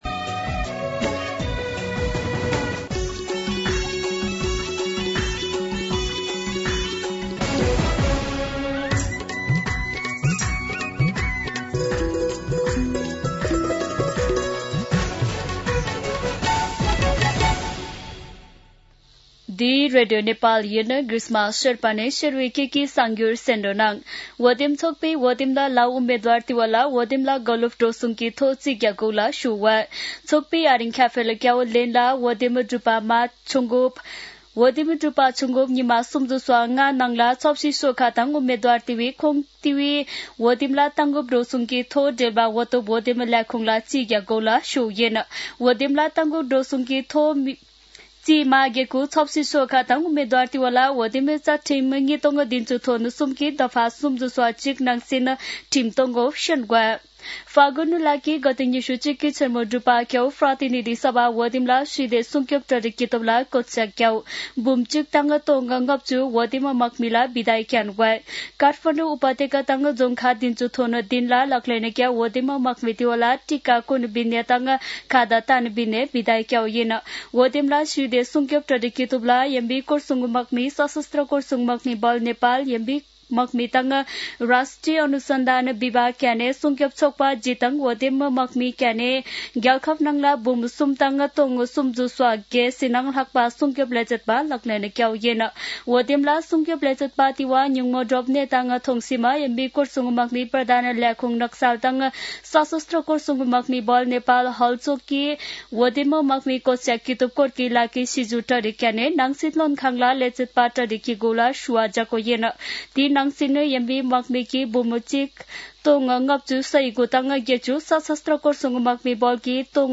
शेर्पा भाषाको समाचार : २९ फागुन , २०८२